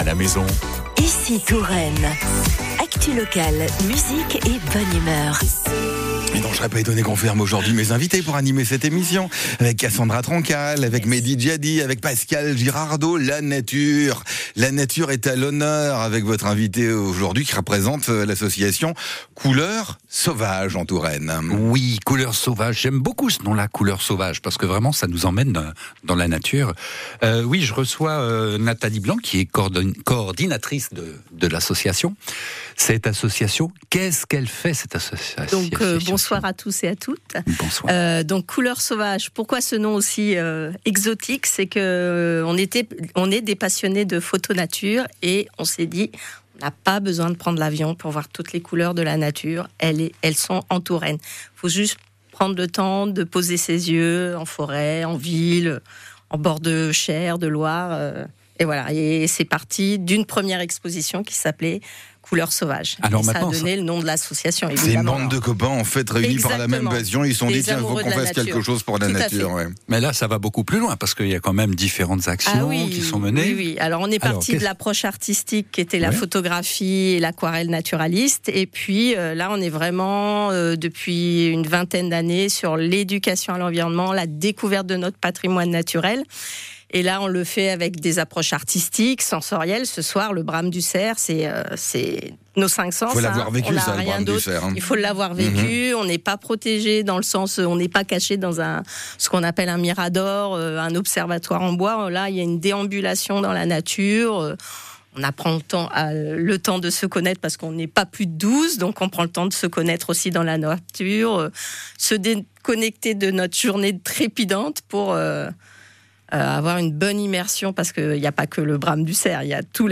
Couleurs Sauvages à la radio avec Ici Touraine